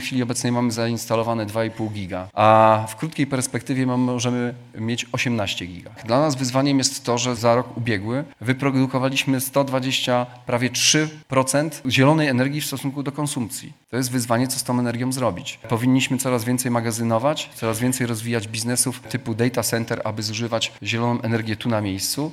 Podczas Forum Ekologicznego, które odbywa się dzisiaj w Szczecinie, Geblewicz mówił między innymi o tym, że wiąże się to z wyzwaniami związanymi z zarządzaniem systemem energetycznym.
Forum Ekologiczne, na którym gości między innymi Rafał Trzaskowski, odbywa się w szczecińskim Teatrze Polskim.